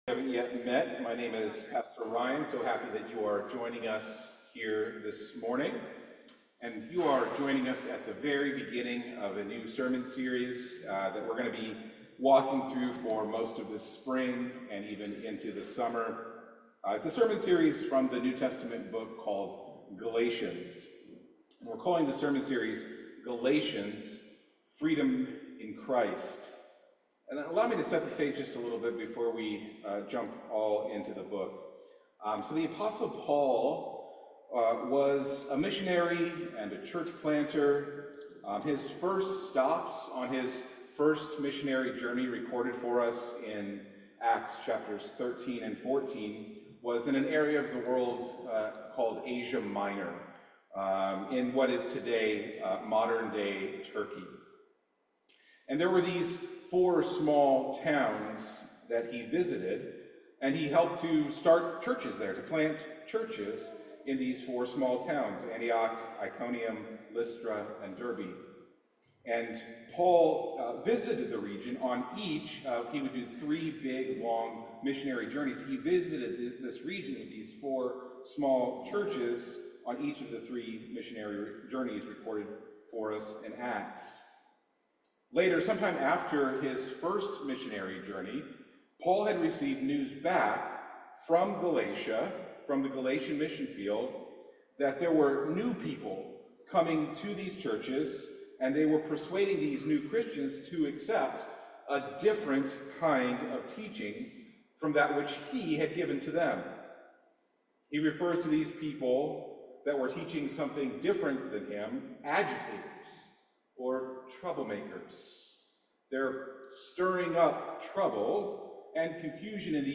The intro sermon focuses on introducing us to Paul, the people churches of Galatia, the theological troubles that were being brought into the communities, and finally a look at the Gospel of Jesus that brings actual freedom. This is because true freedom is not what we have to do for God in order to be made right, but has everything to do with what God has done for us in Jesus Christ.